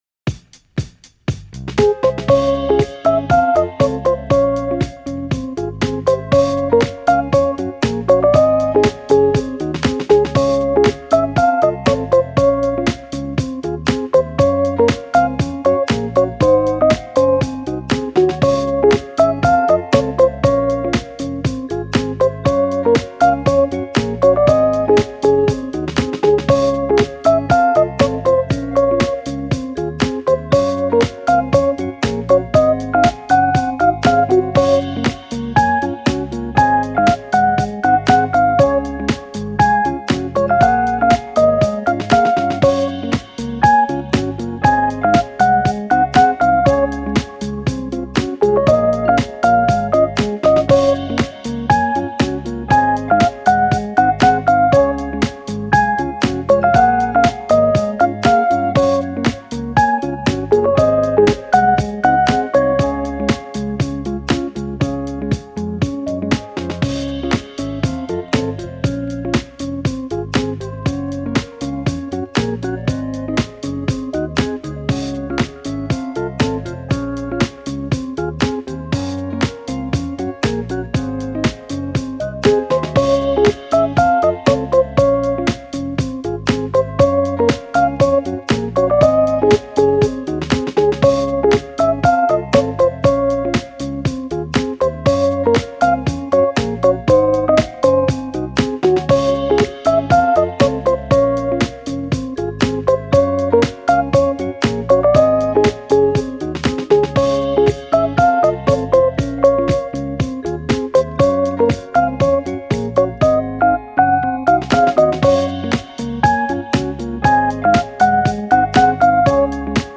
チル・穏やか , フリーBGM , 明るい・ポップ - チルポップ , ローファイ , 可愛らしい , 自分の世界